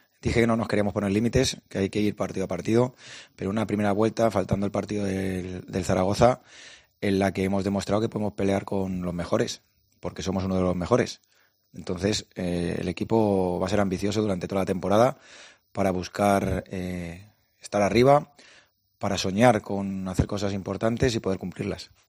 "Dije que no nos queremos poner límites. Podemos pelear contra los mejores porque somos uno de los mejores. El equipo va a ser ambicioso durante toda la temporada para estar arriba y para soñar con cosas importantes y poder cumplirlas", dijo en la rueda de prensa previa al choque.